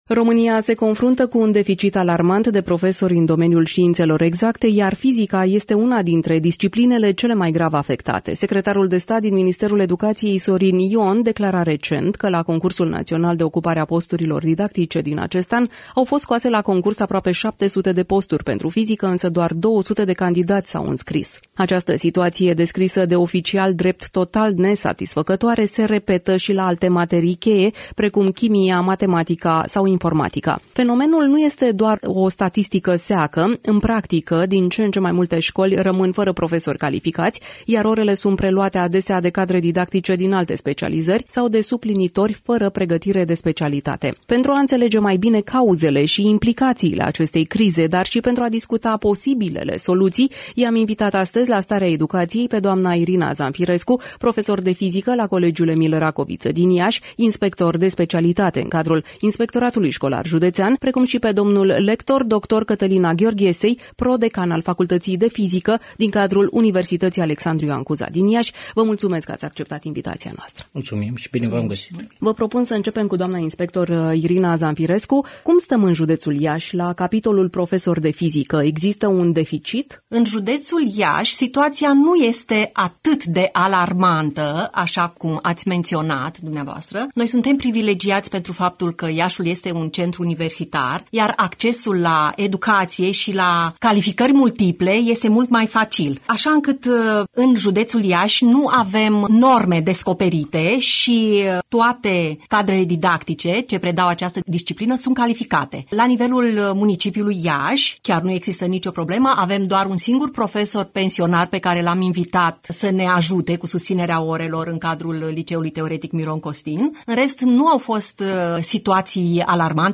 Varianta audio a interviului: Share pe Facebook Share pe Whatsapp Share pe X Etichete